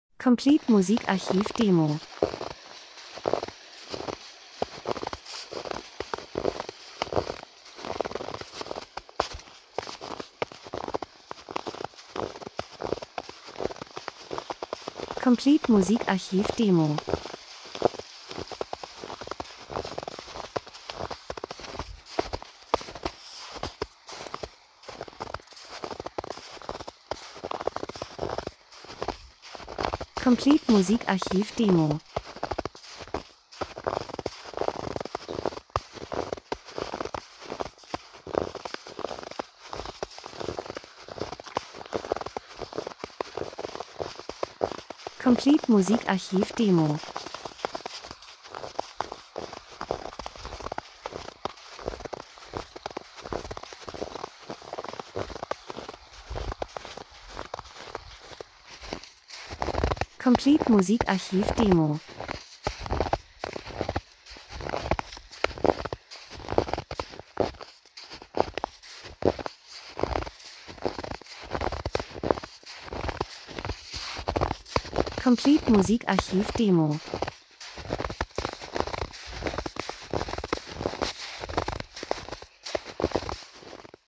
Winter - Spaziergang durch tiefen Schnee 01:23